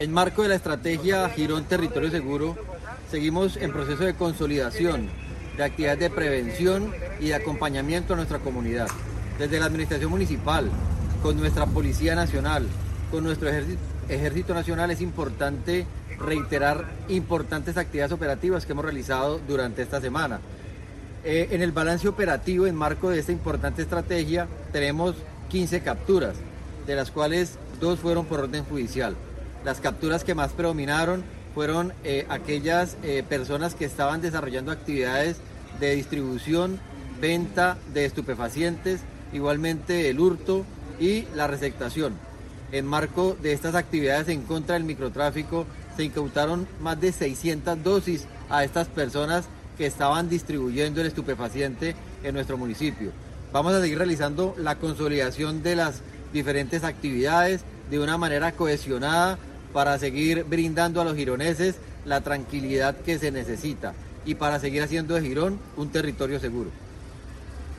Juan Carlos Pinto, Secretario de Seguridad.mp3